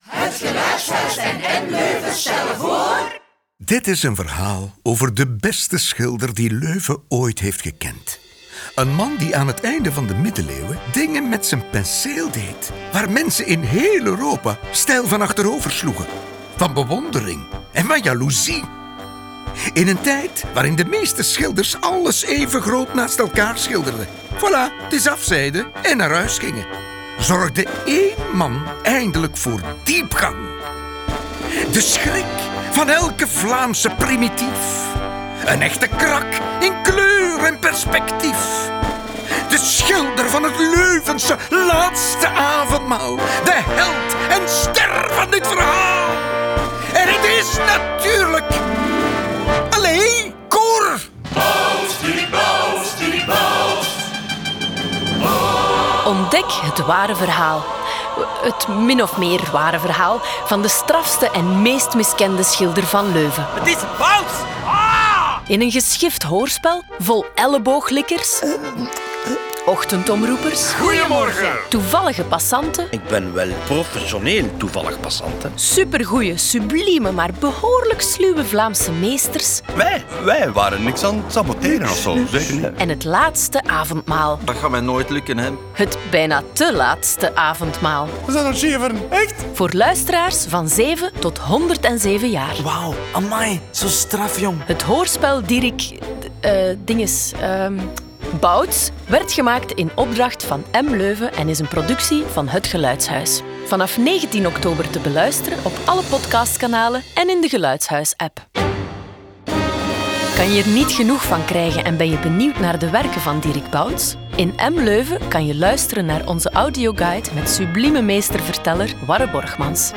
Dieric Bouts krijgt als eerste Vlaamse Meester een eigen hoorspel
Trailer 'Dieric Dinges Bouts'.mp3